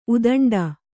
♪ udaṇḍa